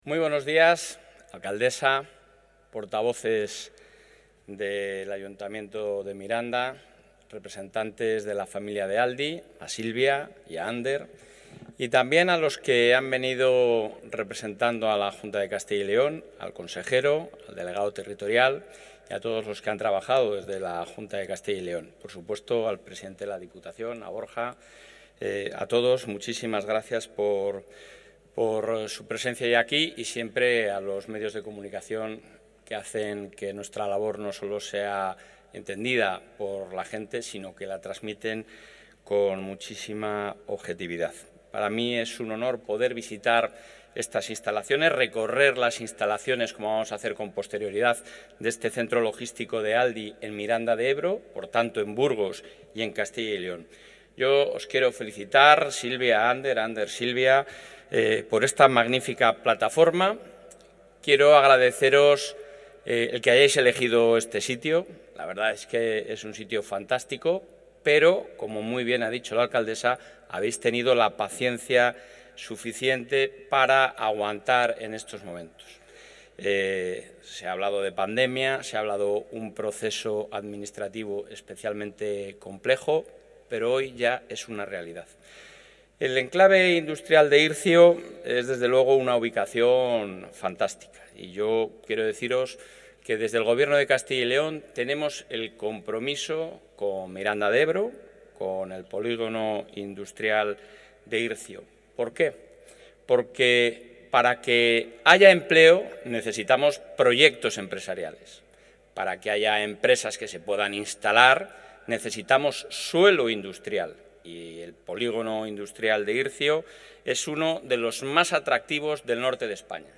Intervención del presidente de la Junta.
Fernández Mañueco ha participado en la inauguración de la nueva plataforma logística de Aldi, que ha contado con el apoyo del Ejecutivo autonómico en la resolución de problemas legales y con la venta del terreno al 50 %, dentro del Plan Industrial.